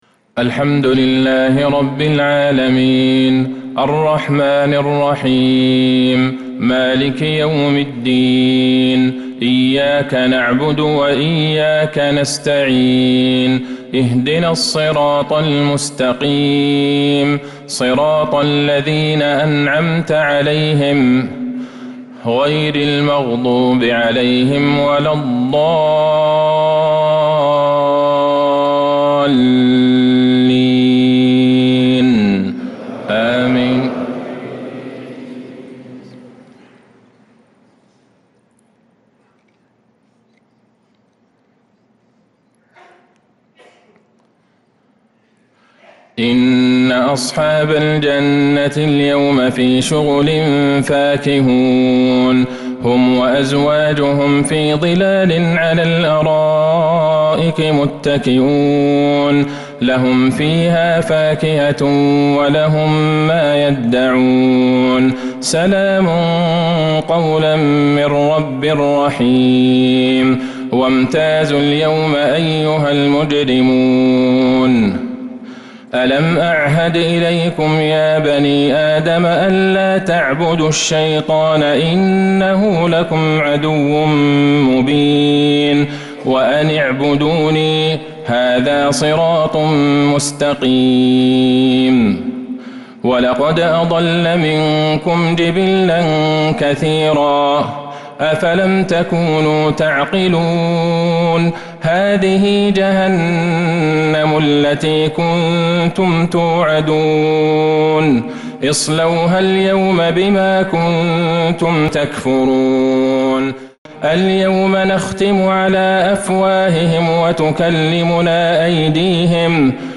عشاء الخميس 8 محرم 1447هـ خواتيم سورة يسٓ 55-83 | Isha prayer from Surah Yaseen 3-7-2025 > 1447 🕌 > الفروض - تلاوات الحرمين